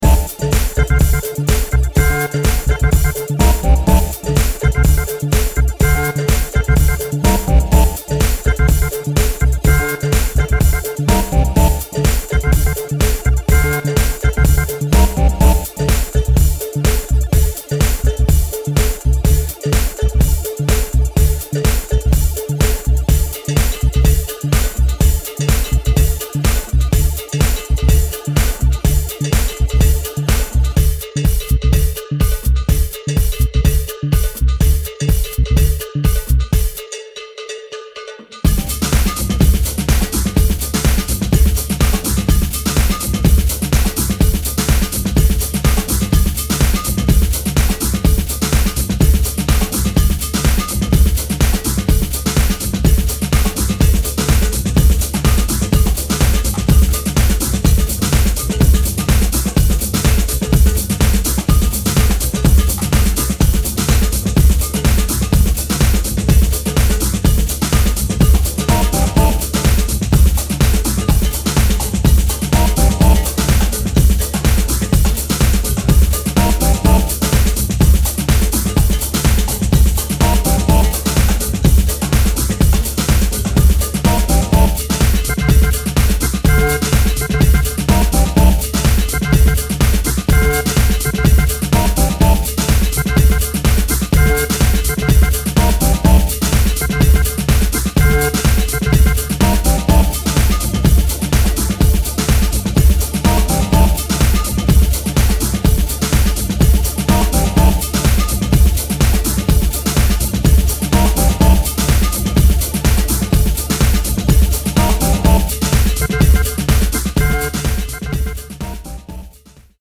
bouncy, percussion-heavy and organ-driven journey